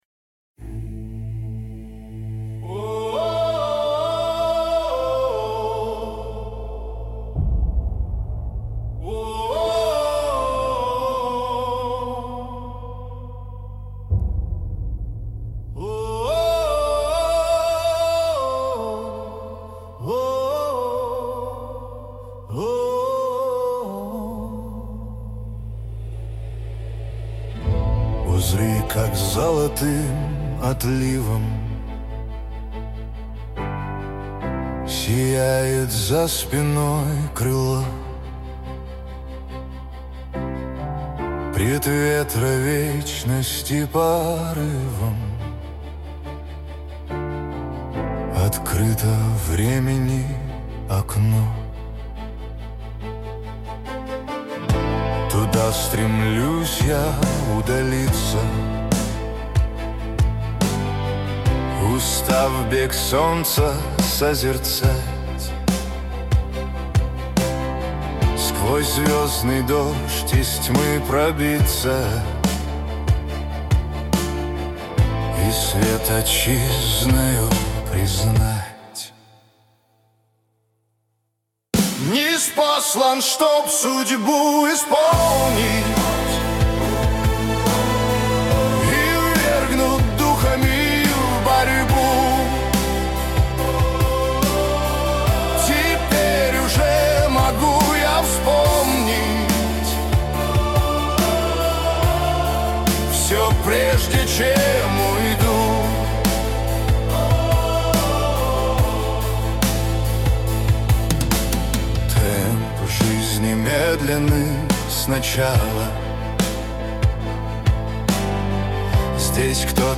Музыкальный хостинг: /Рок